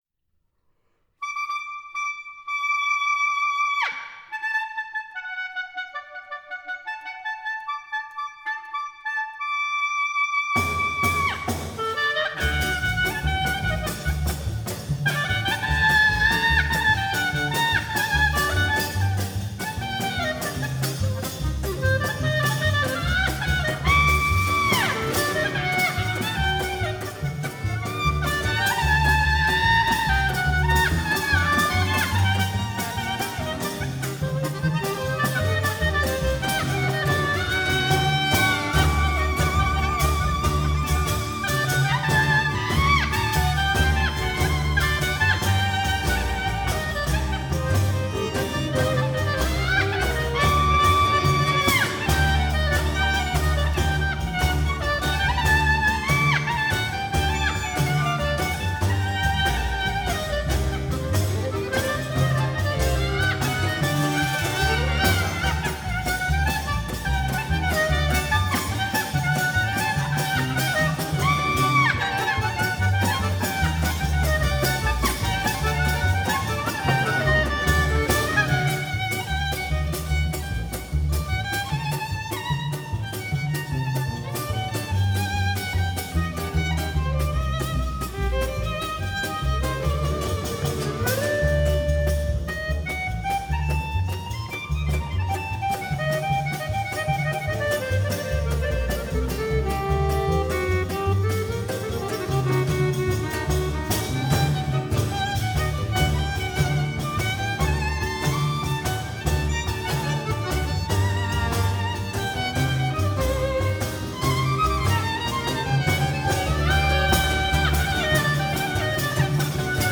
кларнет
Genre: Folk, World,